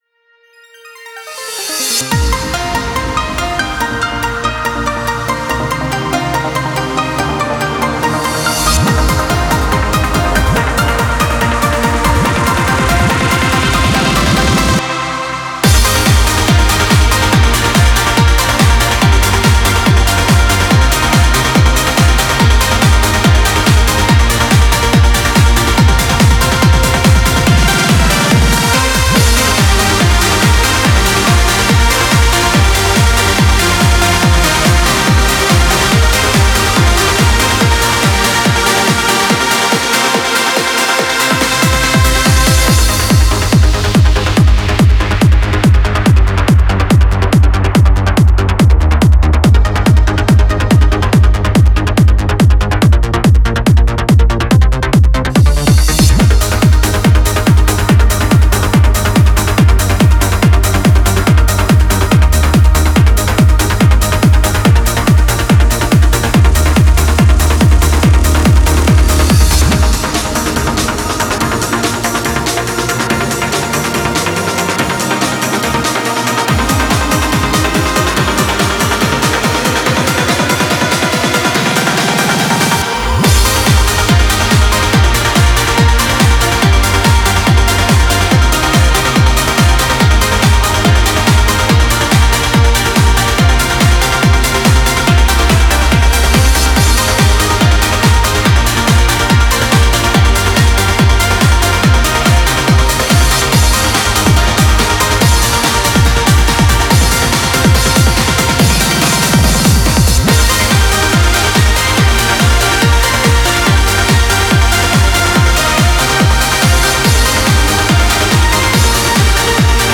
Genre : Trance
BPM : 142 BPM
Release Type : Bootleg
Remix-Edit Instrumental